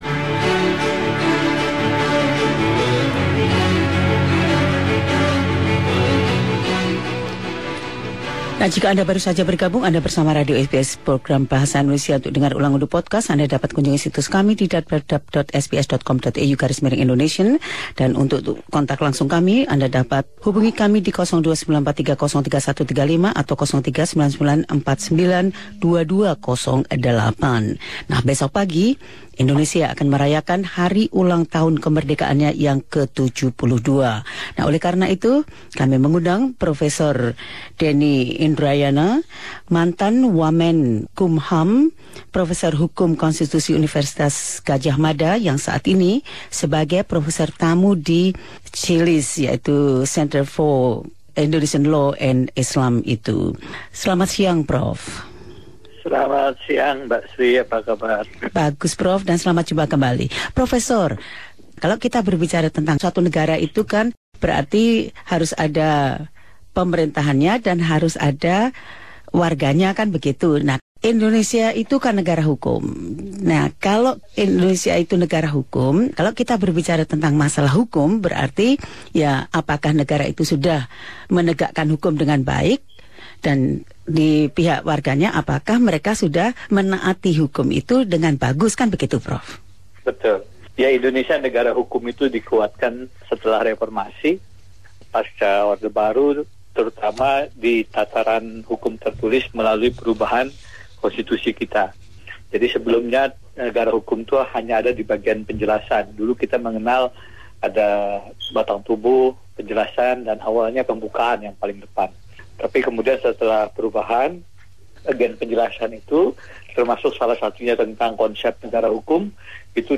Prof Denny Indrayana, mantan Wakil Menteri Hukum dan Hak Asasi Manusia, professor hukum konstitusi di Universitas Gajah Mada yang saat ini sebagai Profesor Tamu di Sekolah Hukum Universitas Melbourne, membahas tentang sistim hukum dan penegakannya di Indonesia.